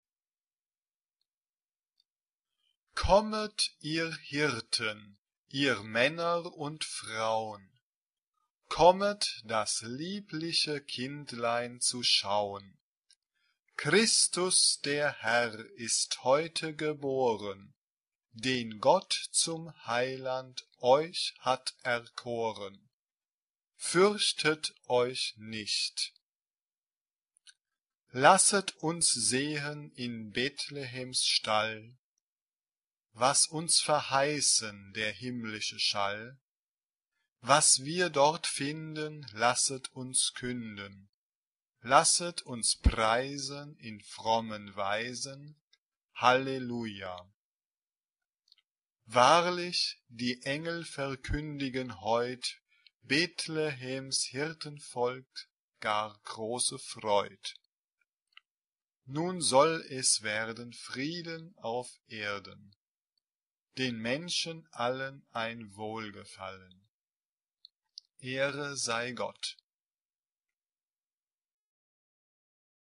SSA (3 voices women) ; Full score.
Christmas carol.
Mood of the piece: joyous Type of Choir: SSA (3 women voices )
Tonality: F major